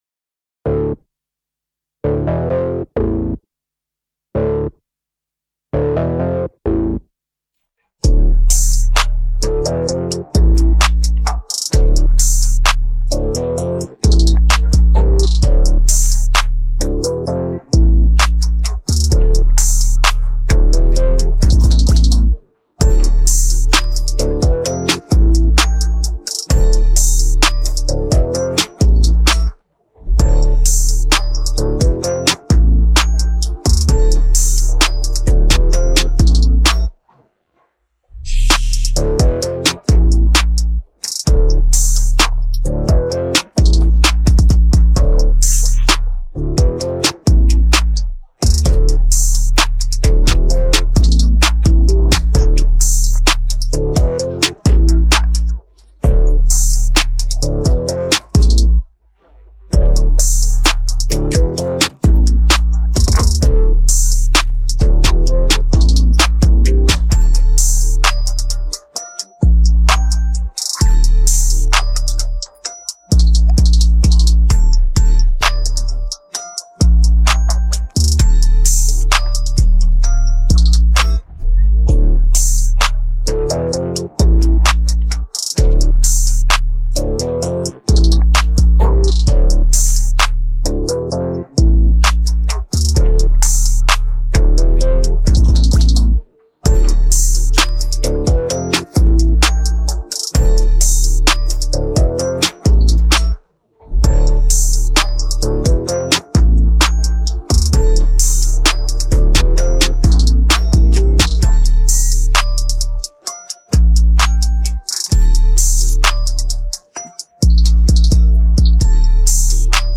instrumental version